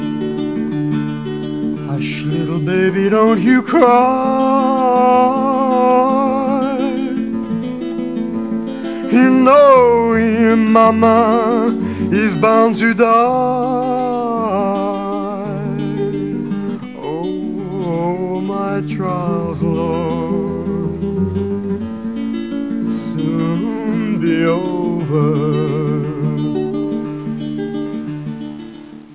The sound is lively, the impact is emotional.